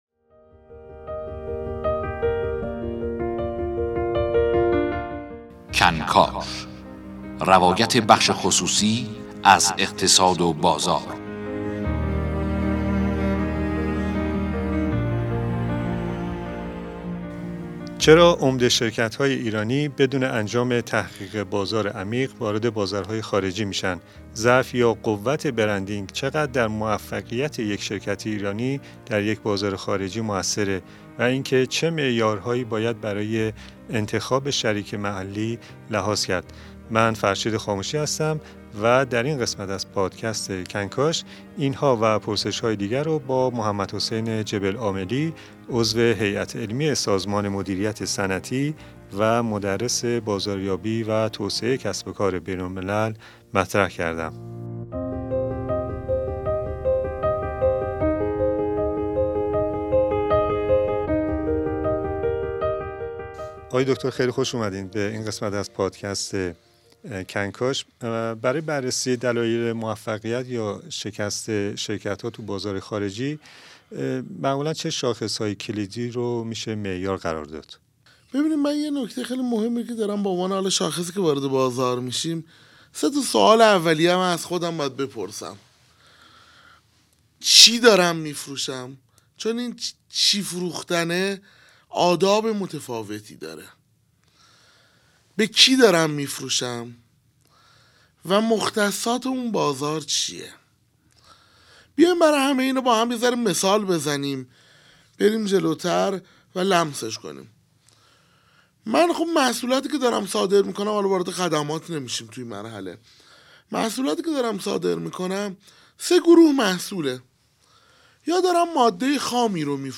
پادکست کنکاش در گفت‌و‌گو با کارشناسان و صاحبان ‌نظر و ایده‌، مسائل و راهکارهای ارتقای کسب‌و‌کار بنگاه‌ها مورد بحث و بررسی قرار می‌گیرد.